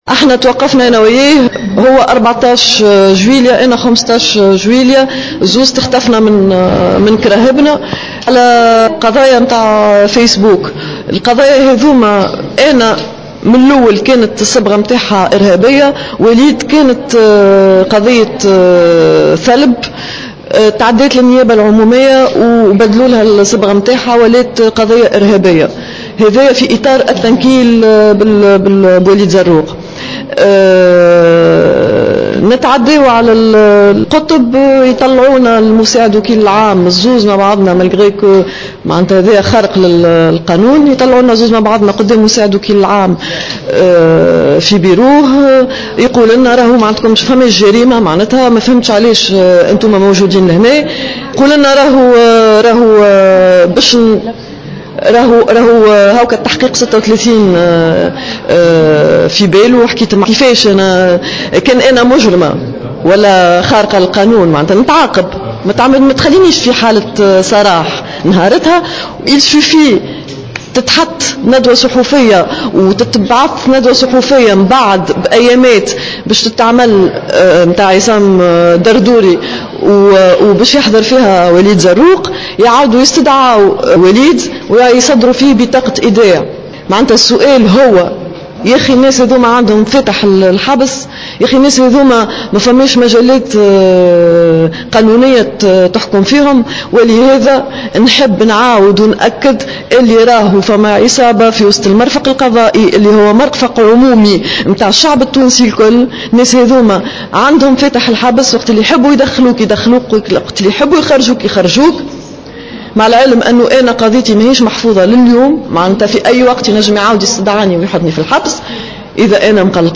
خلال ندوة صحفية اليوم بمقر الرابطة التونسية للدفاع عن حقوق الإنسان